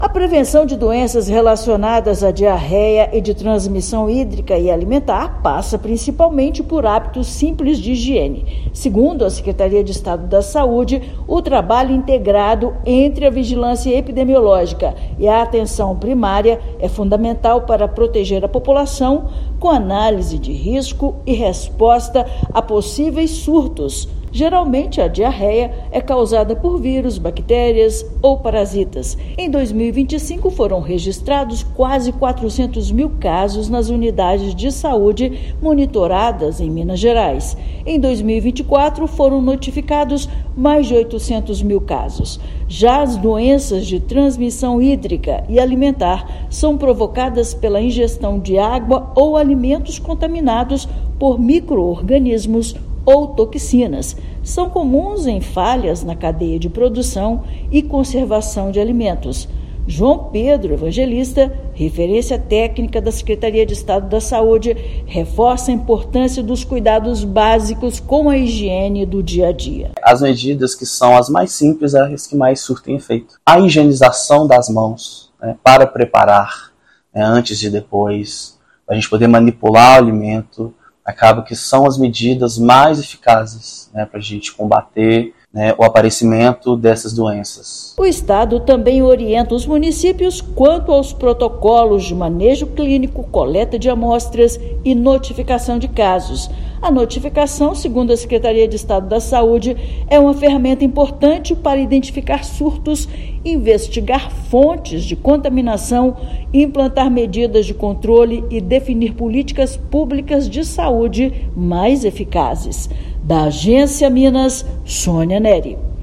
Trabalho integrado entre Vigilância Epidemiológica e Atenção Primária fortalece campanhas educativas e notificação de surtos. Ouça matéria de rádio.